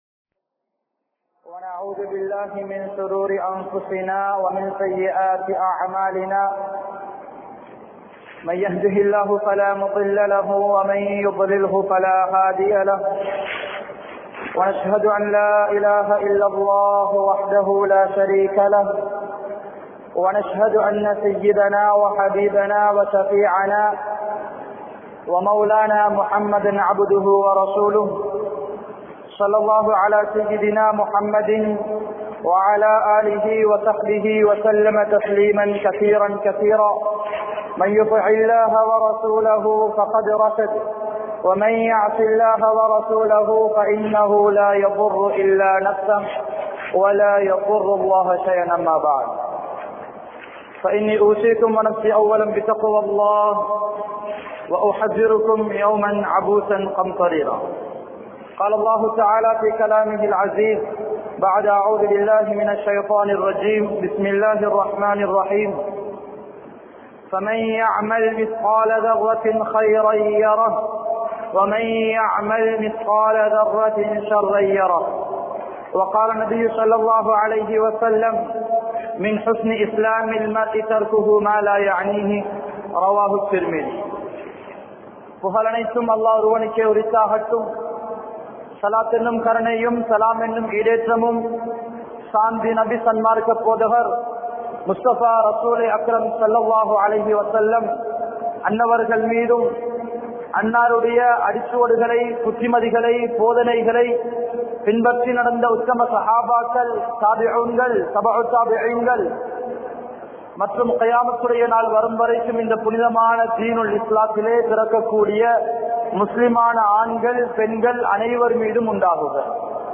Naveena Mediavaal Naraham Selvoar (நவீன ஊடகத்தால் நரகம் செல்வோர்) | Audio Bayans | All Ceylon Muslim Youth Community | Addalaichenai
Akurana, Grand Jumua Masjitth